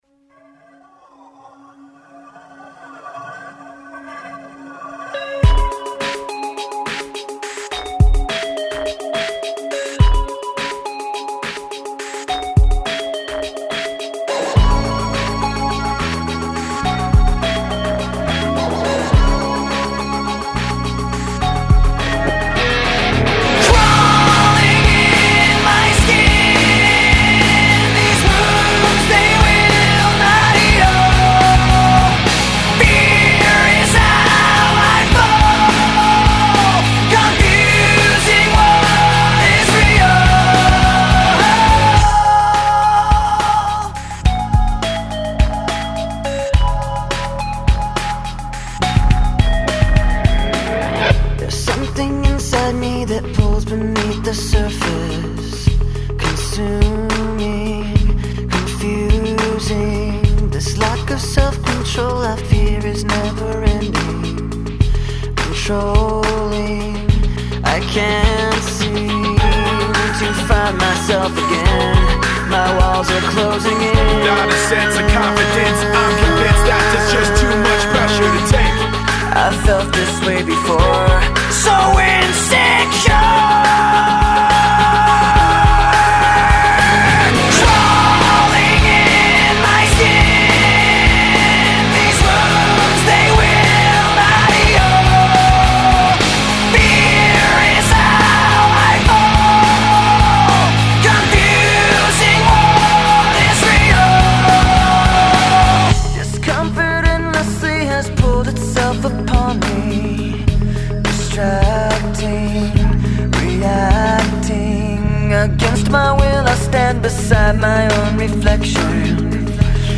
скачать Рок